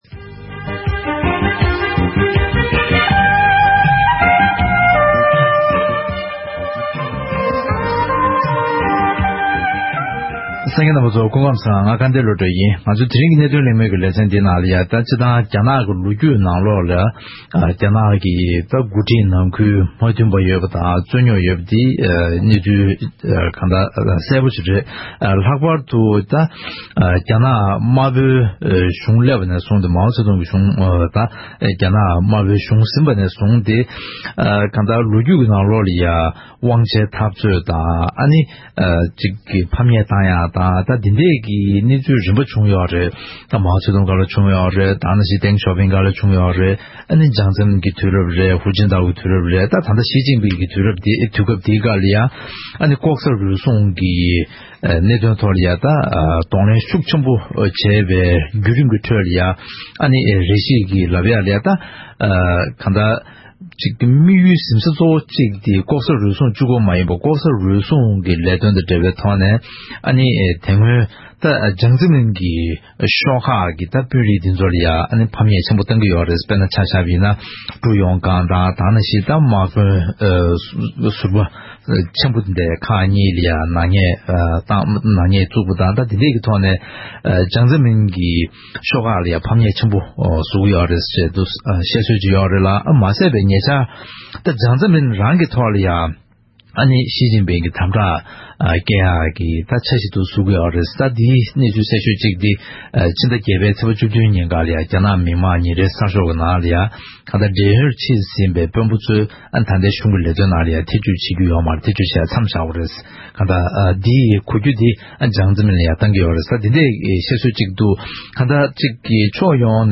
༄༅། །ཐེངས་འདིའི་གནད་དོན་གླེང་མོལ་གྱི་ལས་རིམ་ནང་། རྒྱ་ནག་མི་དམངས་སྤྱི་མཐུན་རྒྱལ་ཁབ་ཀྱི་དཔོན་རིགས་ནང་ཁུལ་དུ་དབང་ཆའི་འཐབ་རྩོད་བྱུང་བཞིན་པ་དང་འབྲེལ་བའི་སྐོར་ལ་གླེང་མོལ་ཞུས་པ་དེ་གསན་རོགས་གནང་།